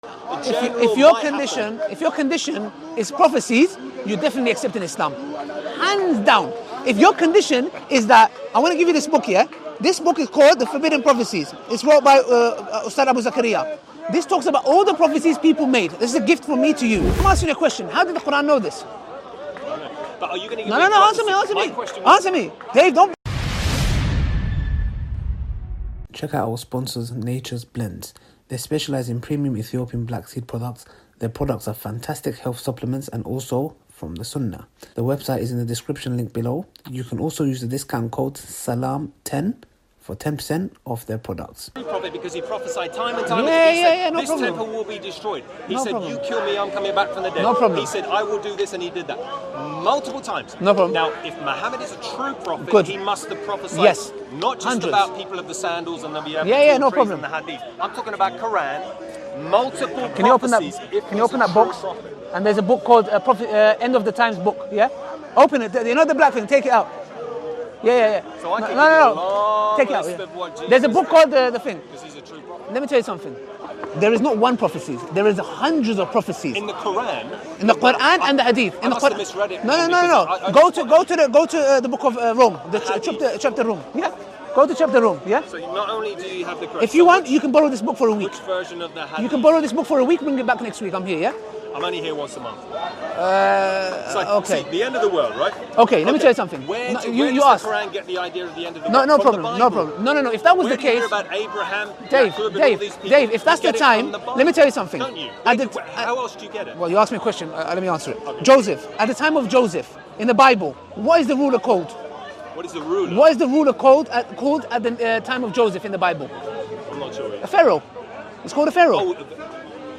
4 MIRACLES LEFT CHRISTIAN SPEECHLESS - SPEAKERS CORNER.mp3